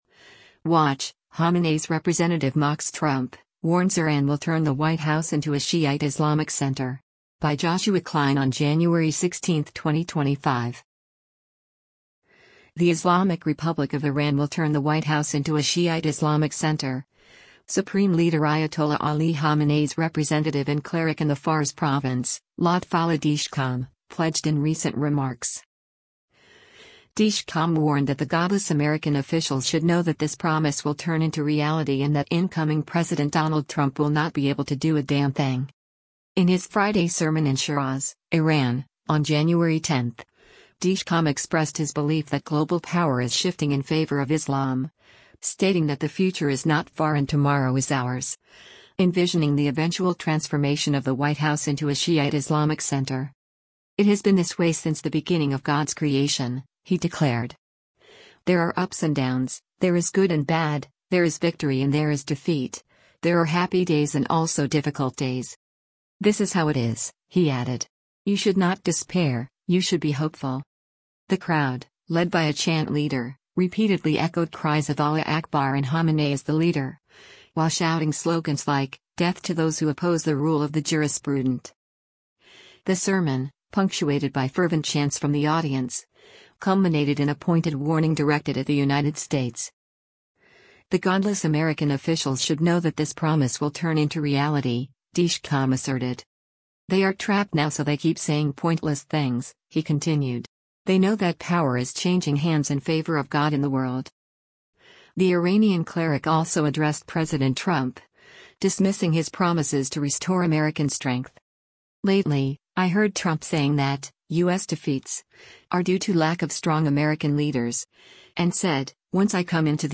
In his Friday sermon in Shiraz, Iran, on January 10, Dezhkam expressed his belief that global power is shifting in favor of Islam, stating that “the future is not far” and “tomorrow is ours,” envisioning the eventual transformation of the White House into a “Shi’ite Islamic center.”
The crowd, led by a chant leader, repeatedly echoed cries of “Allah Akbar” and “Khamenei is the Leader,” while shouting slogans like, “Death to those who oppose the rule of the Jurisprudent.”
The sermon, punctuated by fervent chants from the audience, culminated in a pointed warning directed at the United States.